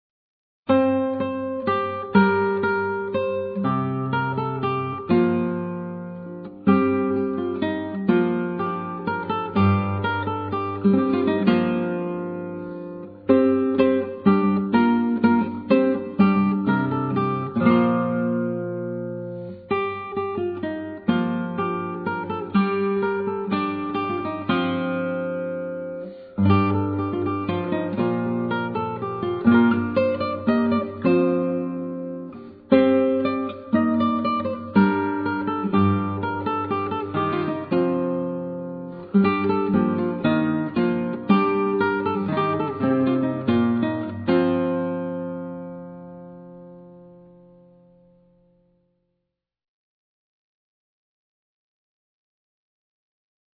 Classical
Solo guitar